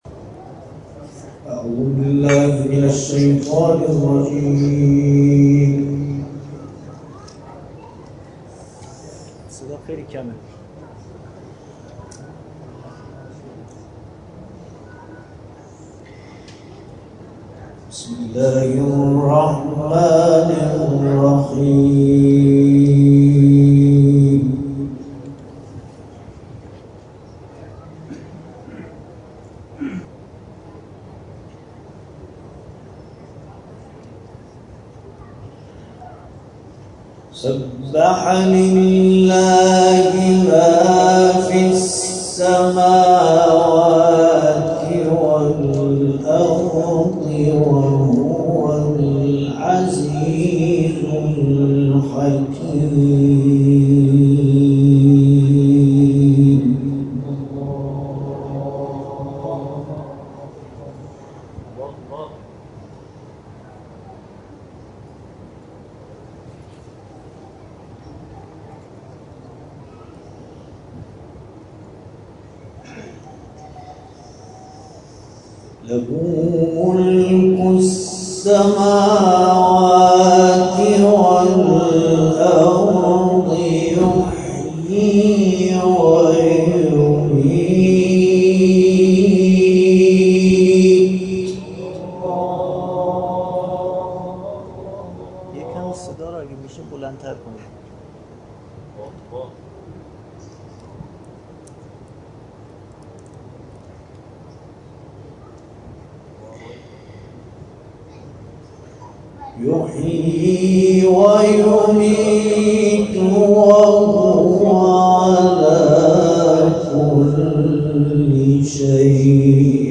گروه جلسات و محافل: محفل انس با قرآن کریم، به همت حوزه بسیج کارمندی حضرت نوح (ع) شهرستان ساوه، با حضور قاری ممتاز کشومان
تلاوت